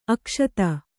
♪ akṣata